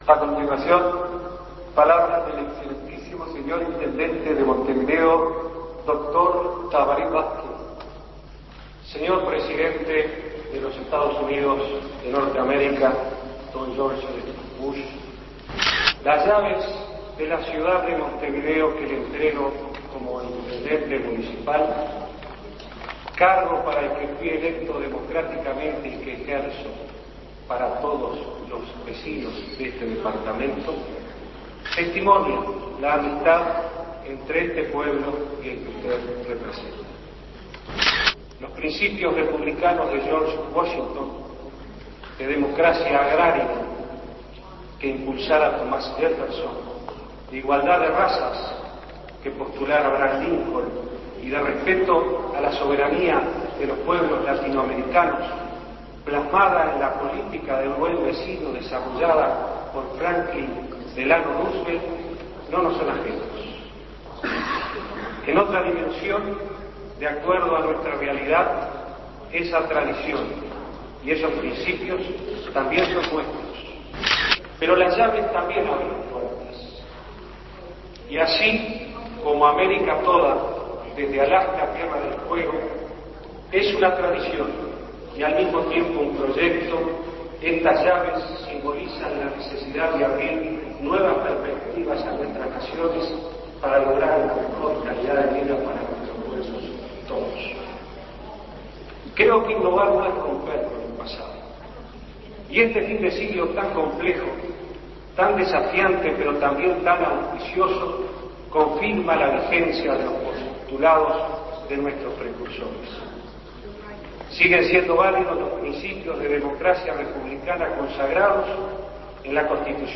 Escuche el discurso del entonces intendente Tabaré Vázquez al entregarle las llaves de la ciudad a George Bush (padre)